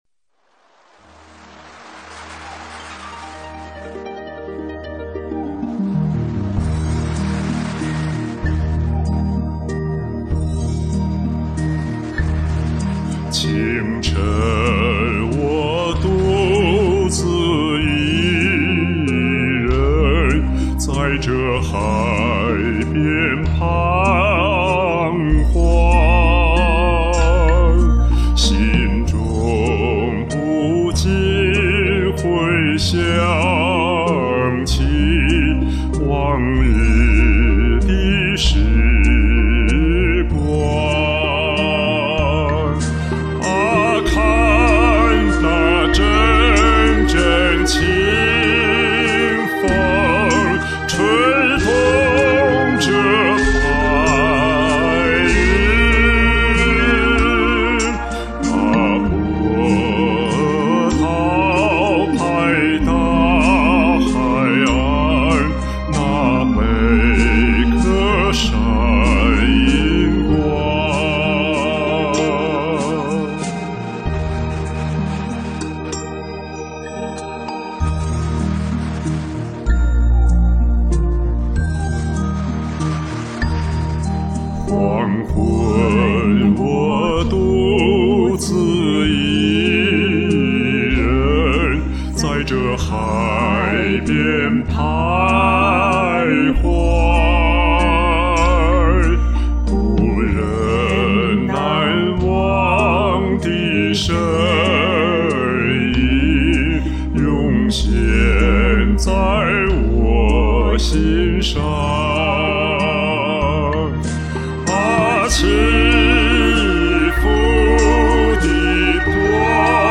二人版